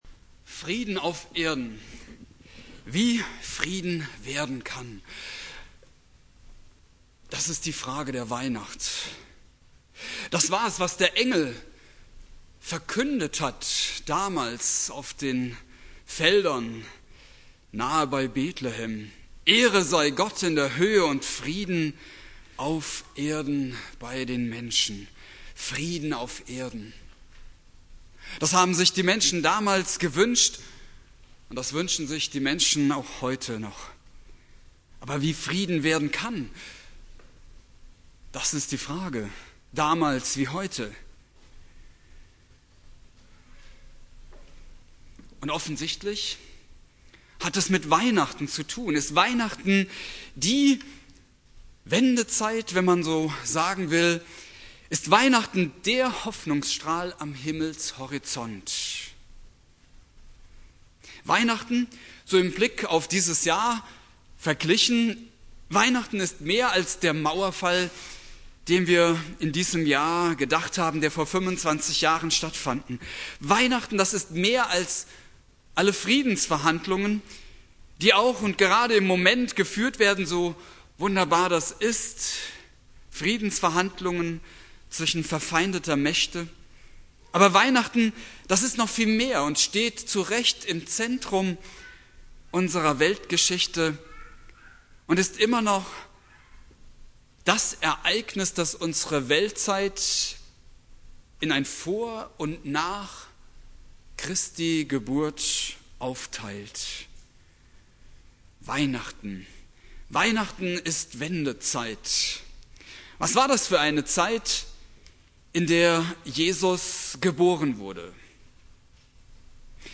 Predigt
Heiligabend Prediger